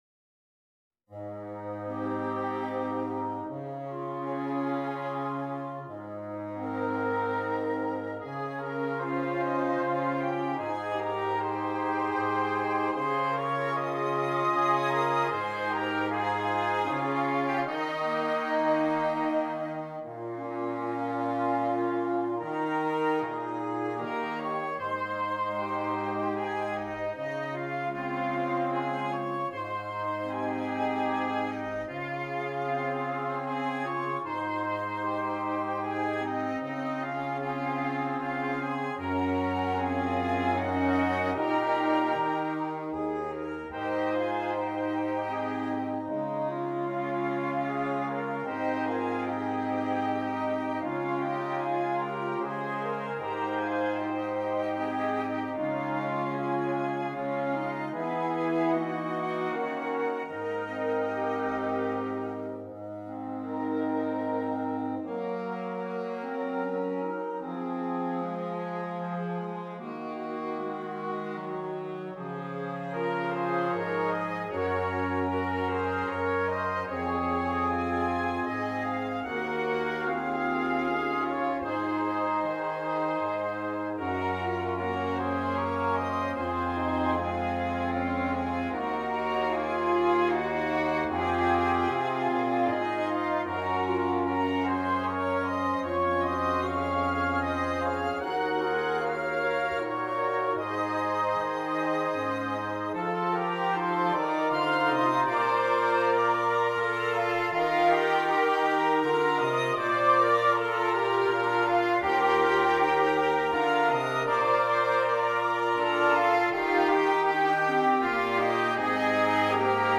Making lazy circles and simply floating on the breeze.